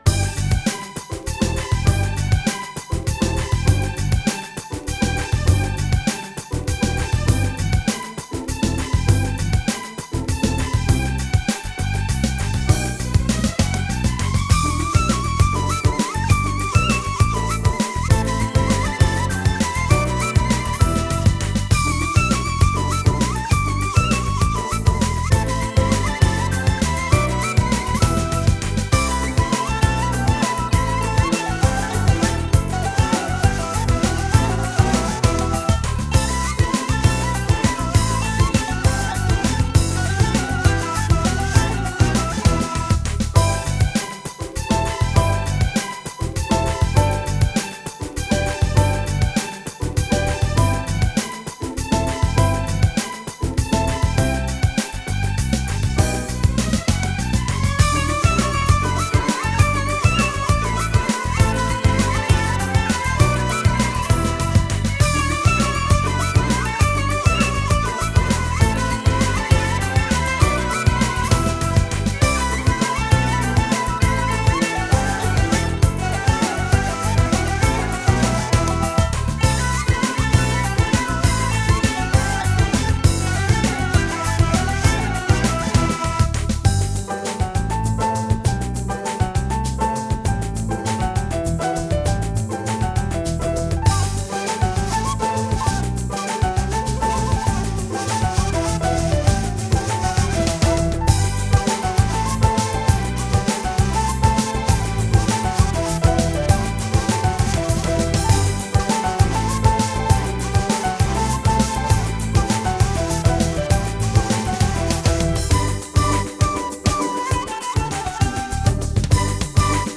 Genre: Oriental, Ethnic, World, Duduk, New Age
guitar
kamancha